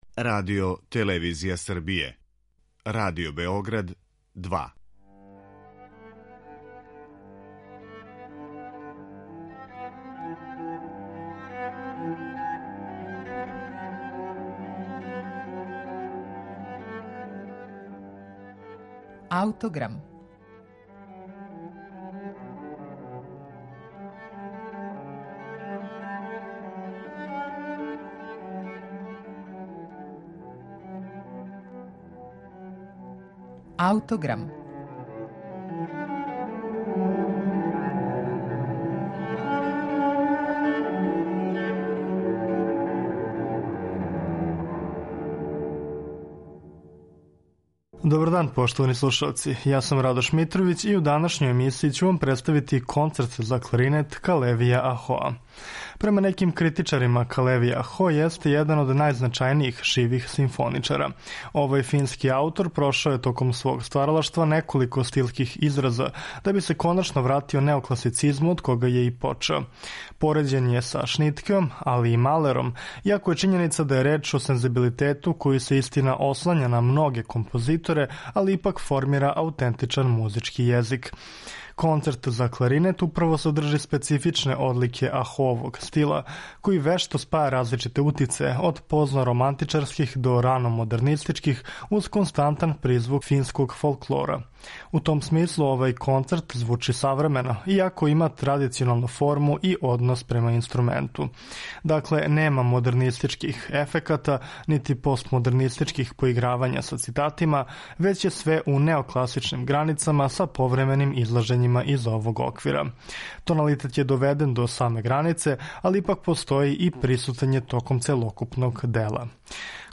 кларинетисте